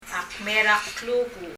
発音